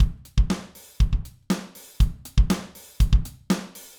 beat_raw-wav.18401